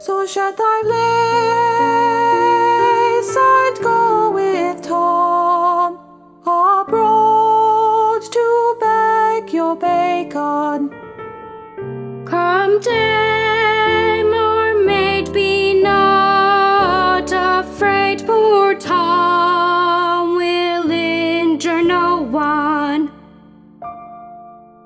Soprano and Alto
Hear bars 201-209 with just Thomasina's part sung
CC_Audition-TrebleHarmony.mp3